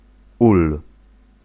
Ääntäminen
US : IPA : [ˈbi.ˌhɑɪv]